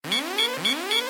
missilewarn.ogg